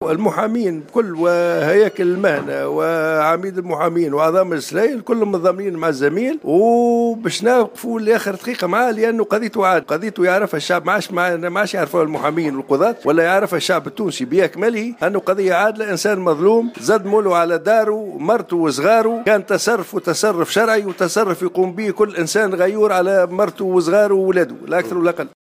وأكد عميد المحامين في تصريح للجوهرة "اف ام" اليوم الخميس أن قضية زميلهم قضية عادلة و أن هياكل المهنة ستعمل على مساندته.